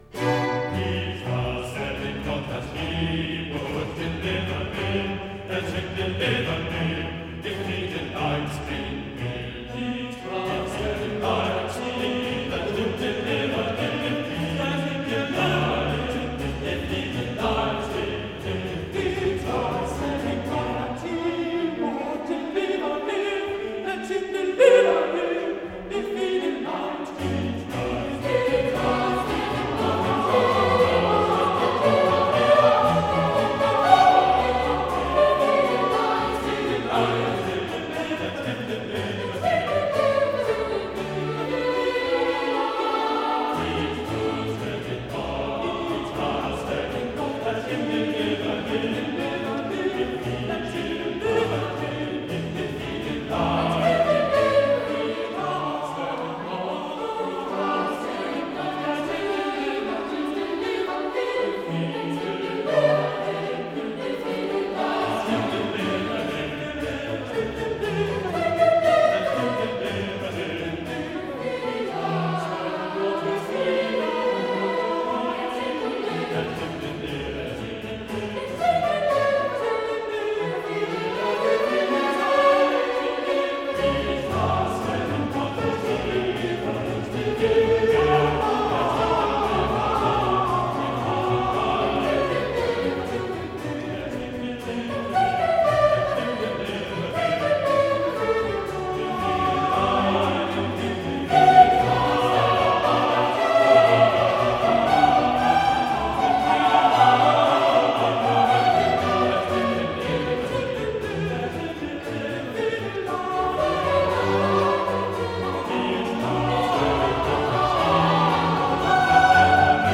Chorus